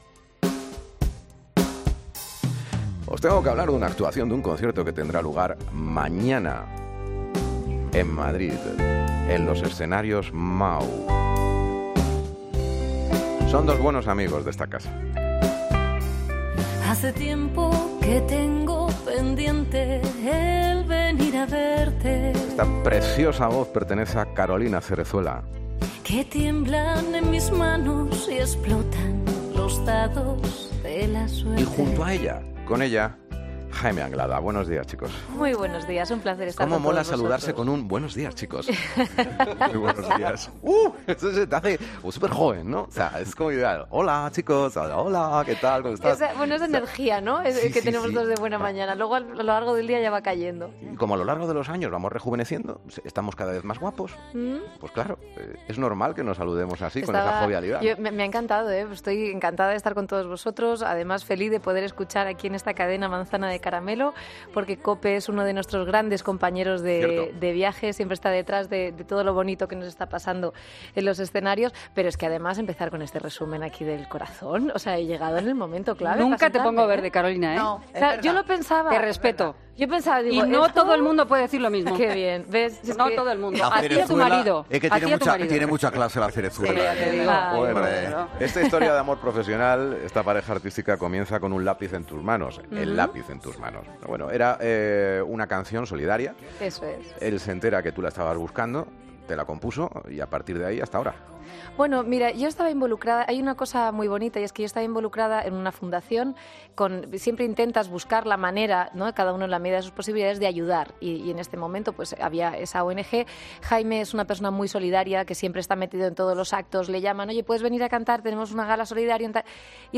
Jaime Anglada y Carolina Cerezuela en el estudio de la Cadena COPE.